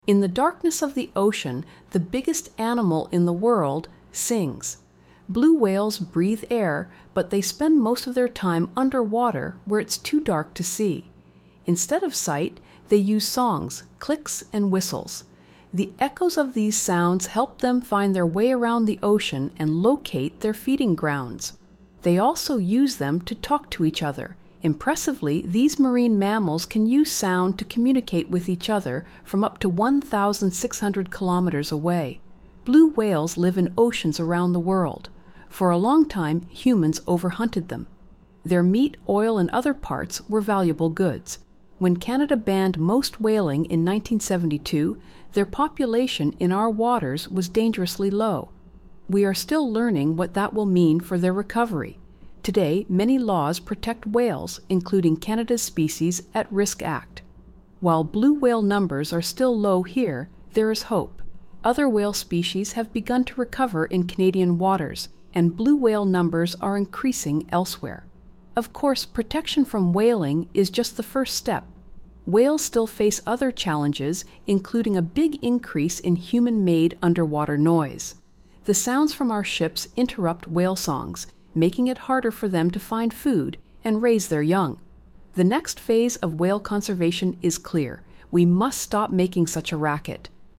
Blue whale
In the darkness of the ocean, the biggest animal in the world sings.
Instead of sight, they use songs, clicks and whistles.
Blue-whale.mp3